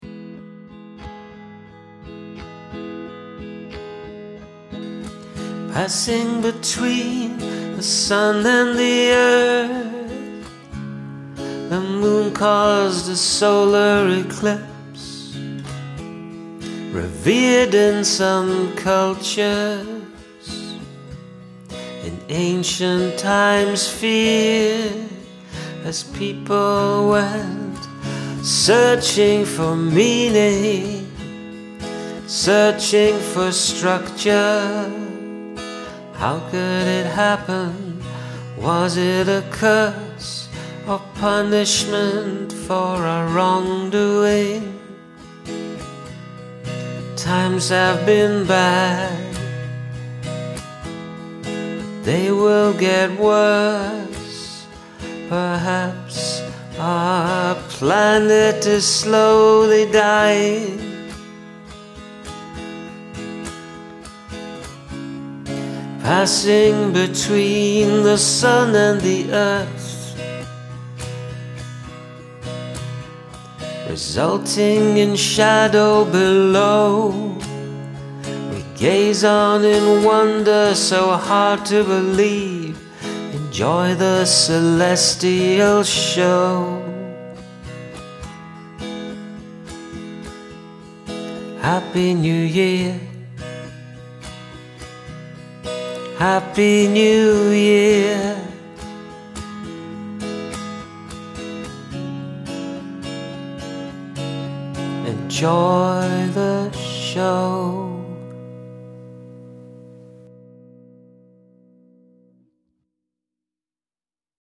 I really like the melody and the pensive lyrics here.